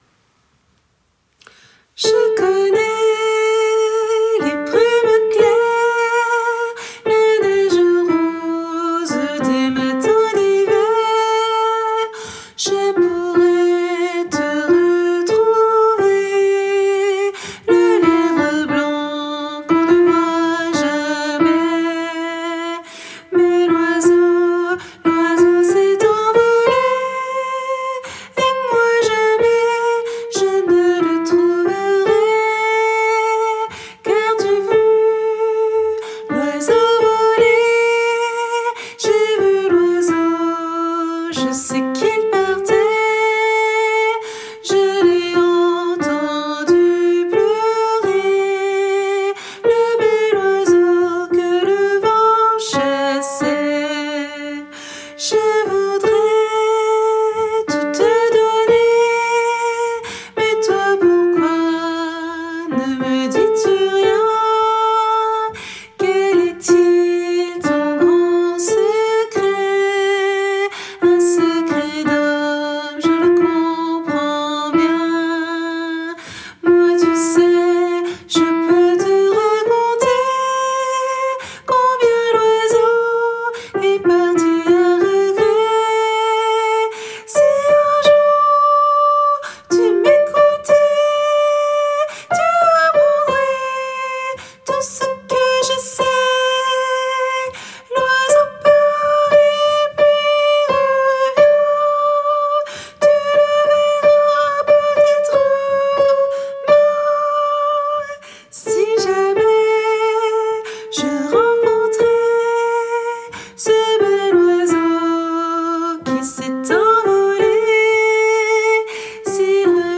- Oeuvre pour choeur à 4 voix mixtes (SATB)
MP3 versions chantées
Soprano